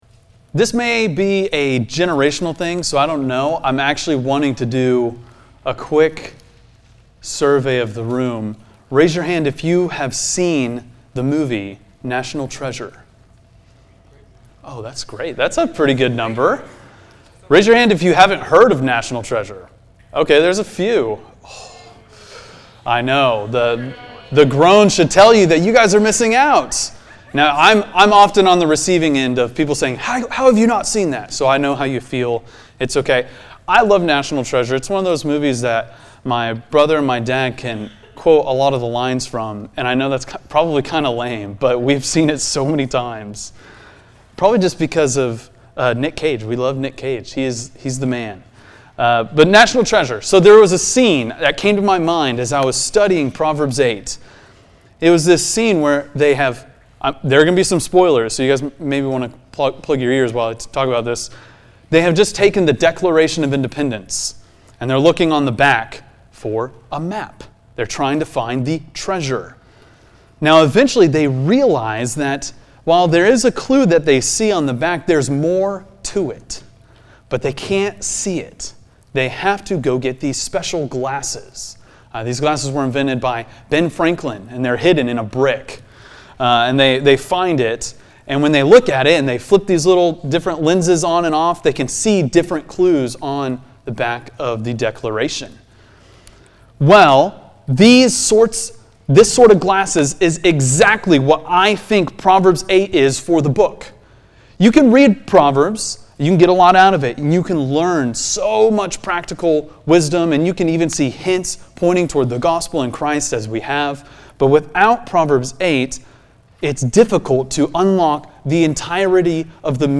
preaches through Proverbs 8.